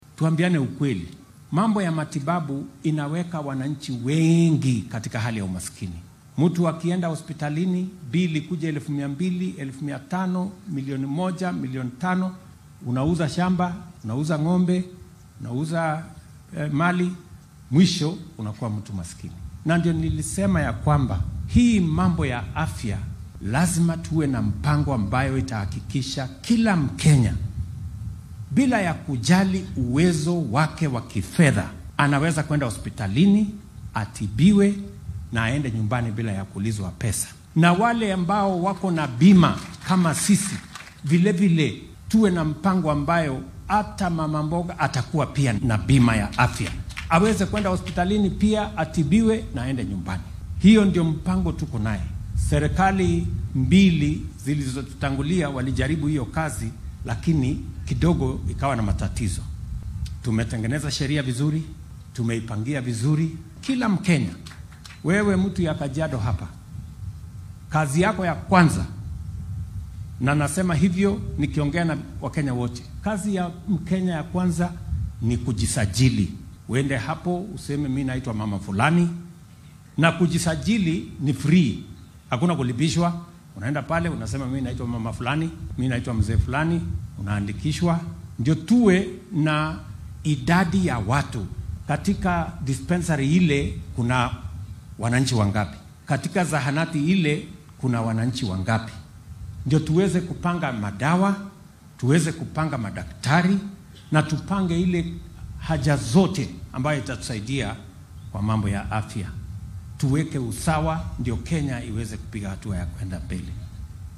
Madaxweynaha dalka William Ruto ayaa sheegay in ujeeddada laga leeyahay barnaamijka caafimaadka guud ee UHC gaar ahaan caymiska caafimaadka bulshada ee Taifa Care ay tahay in la helo sinnaan dhanka daryeelka ah. Ruto ayaa xilli uu ku sugnaa ismaamulka Kajiado sheegay in dowladdu ay qaadday tallaabooyin lagu xaqiijinaya in barnaamijkan lagu guulaysto sida dejinta xeerarka lagama maarmaanka ah.